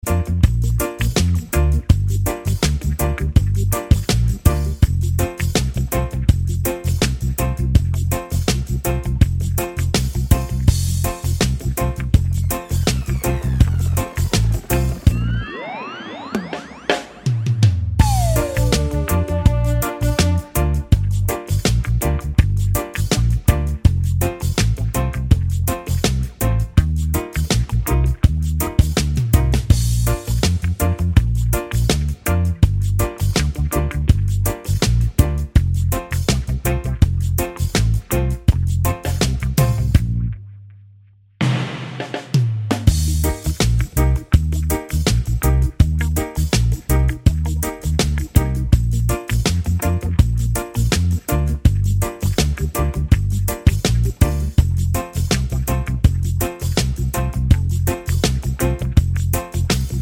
no Backing Vocals Reggae 3:18 Buy £1.50